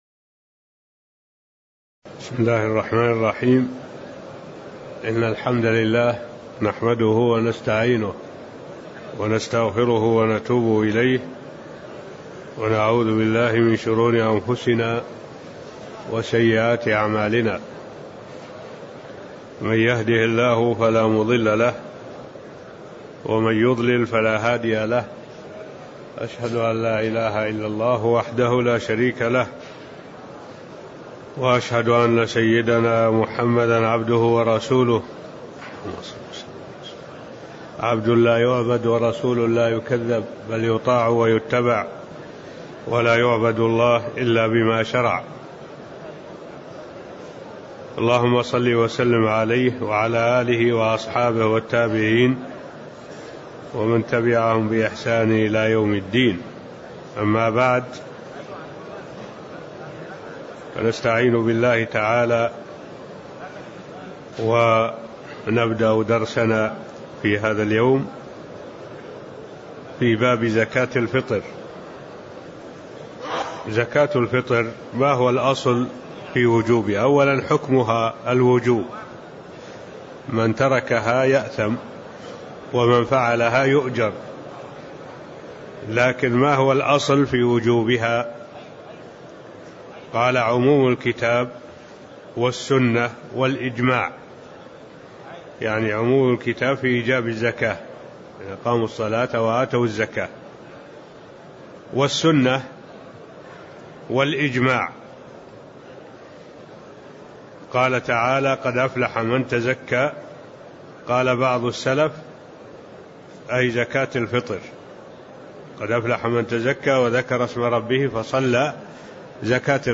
تاريخ النشر ٢٥ رمضان ١٤٢٨ هـ المكان: المسجد النبوي الشيخ: معالي الشيخ الدكتور صالح بن عبد الله العبود معالي الشيخ الدكتور صالح بن عبد الله العبود حكم زكاة الفطر واصلها (005)